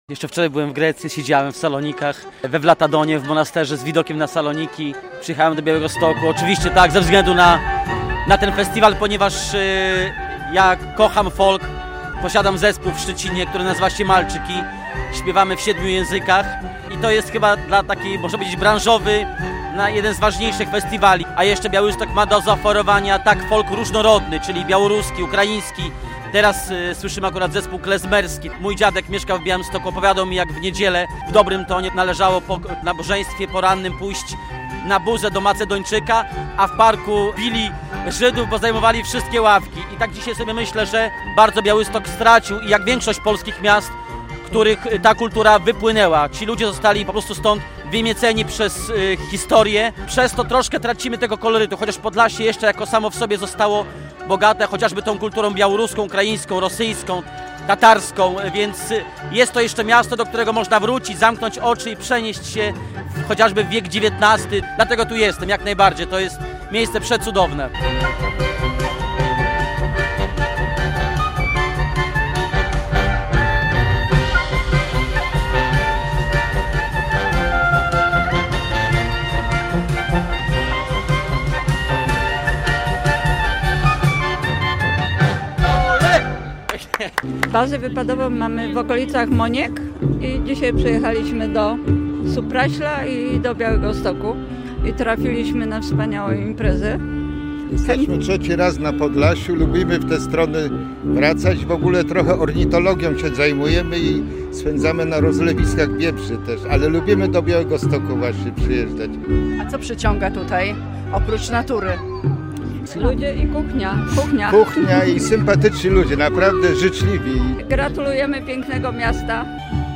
Centrum Białegostoku rozbrzmiewa muzyką z najróżniejszych zakątków Polski.